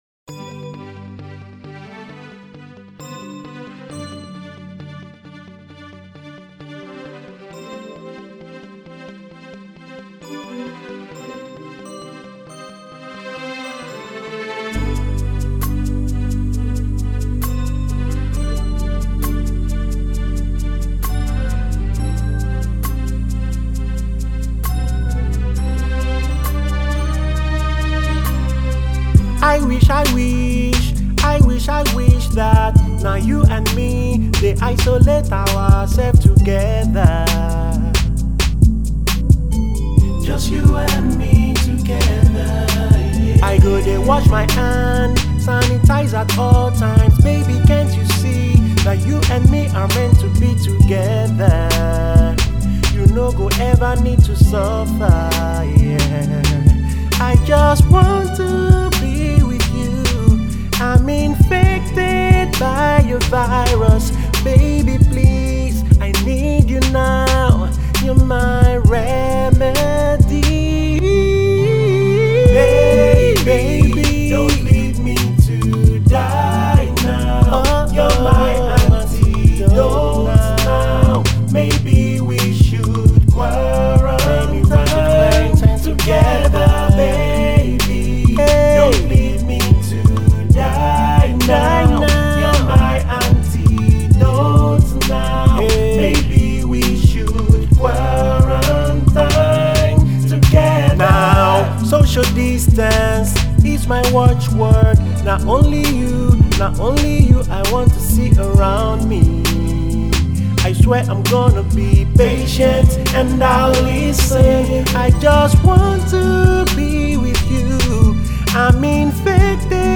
This R&B soulful jam